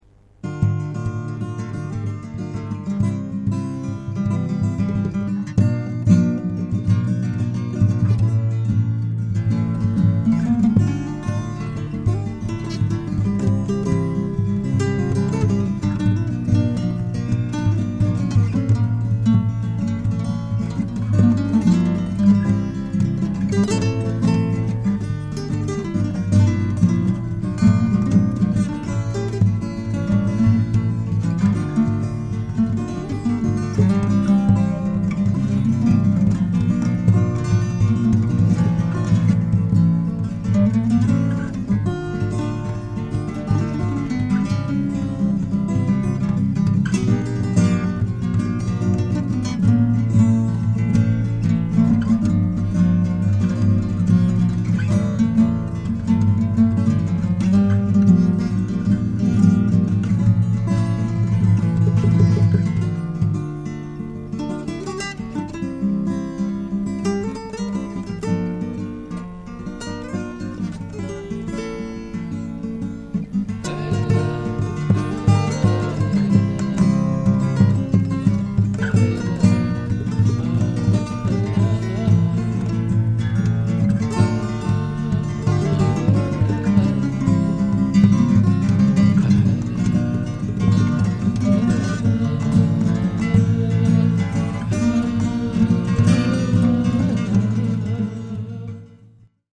Фламенко №1 STEREO (с/с) 01/03/12